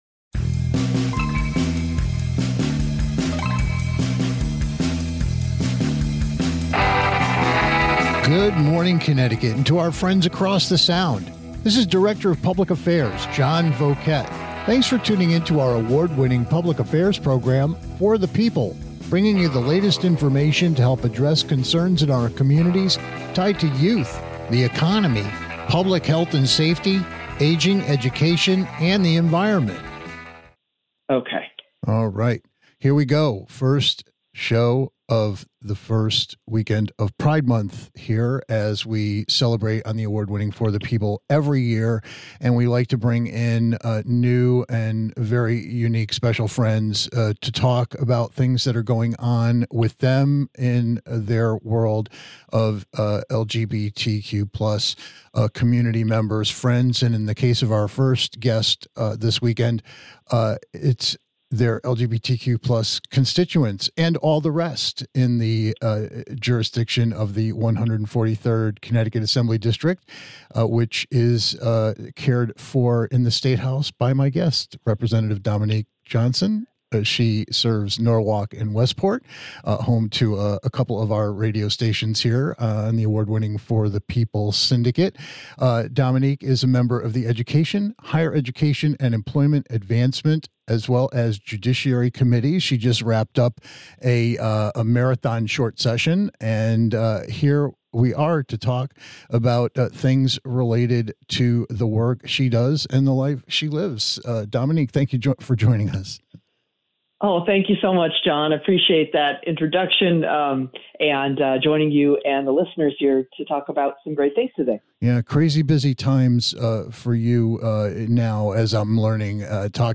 It's the first weekend of June and that means it's Pride Month, so listen in as we participate in an engaging and empowering conversation with Connecticut State Rep Dominique E. Johnson - proudly serving Norwalk and Westport.